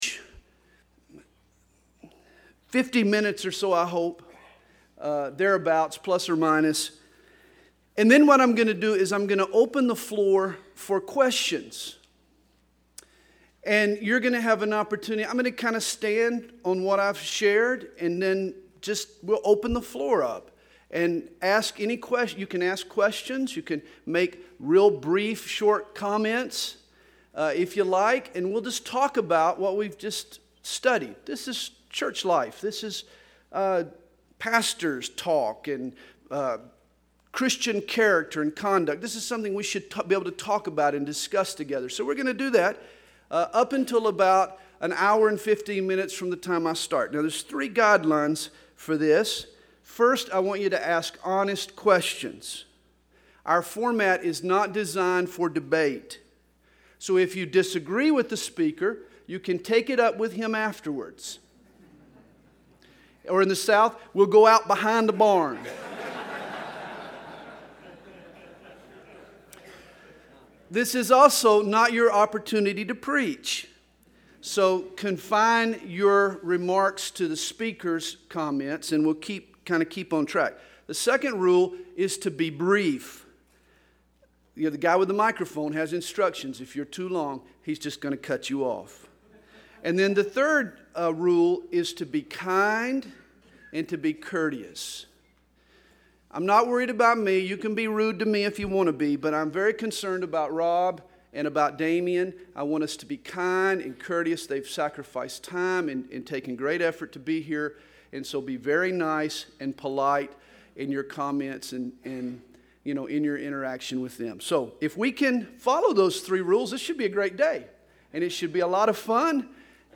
2007 Home » Sermons » Session 4 Share Facebook Twitter LinkedIn Email Topics